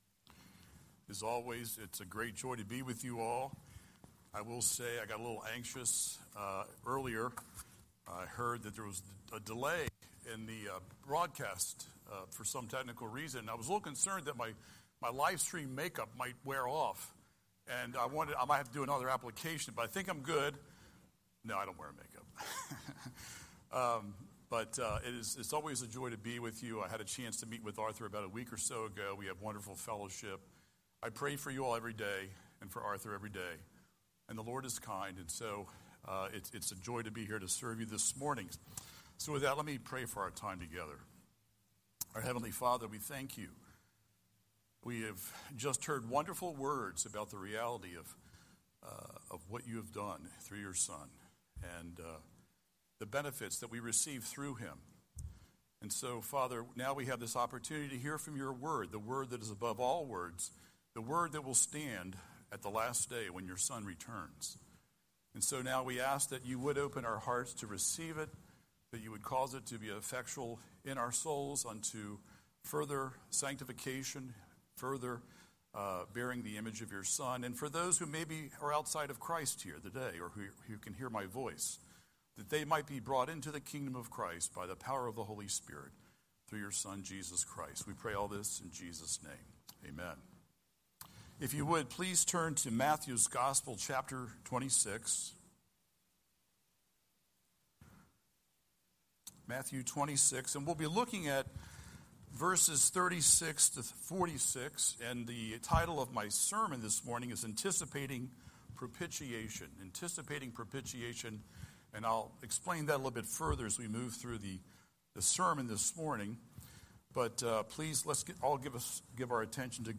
Scripture: Matthew 26:36–46 Series: Sunday Sermon